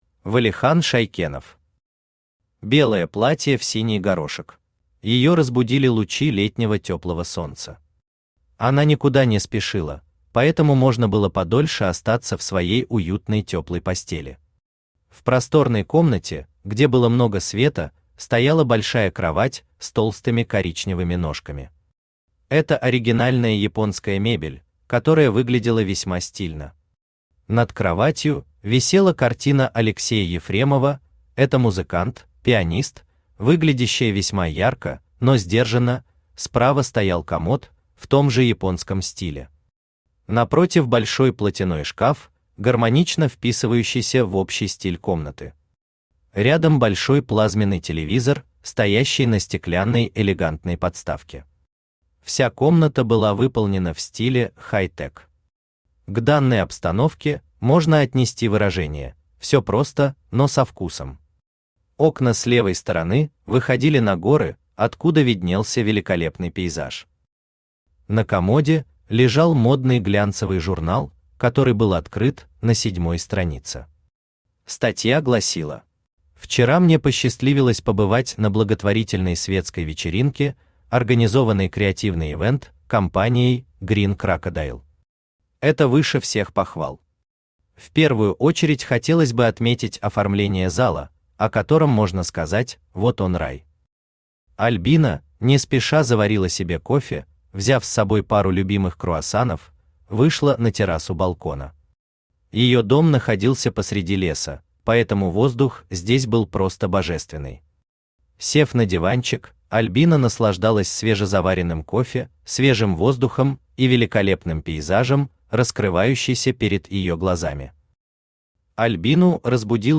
Аудиокнига Белое платье в синий горошек | Библиотека аудиокниг